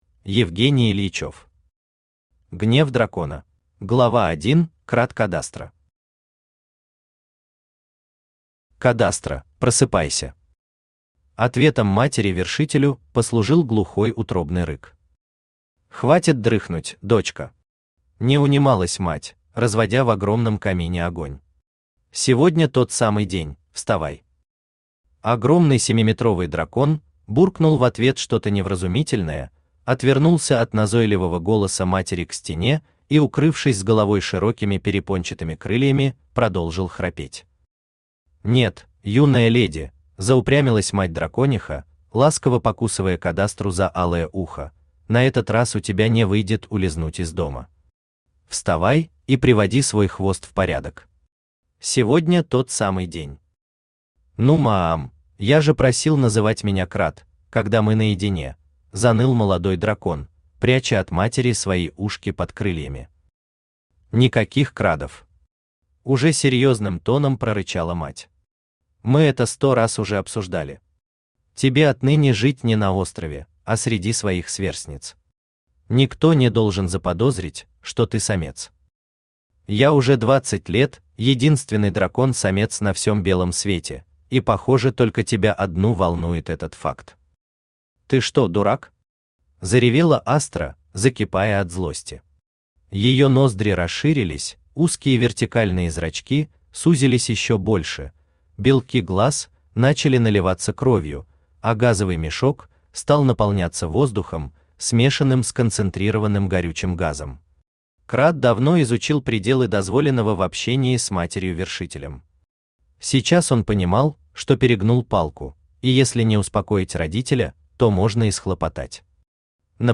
Aудиокнига Гнев дракона Автор Евгений Юрьевич Ильичев Читает аудиокнигу Авточтец ЛитРес.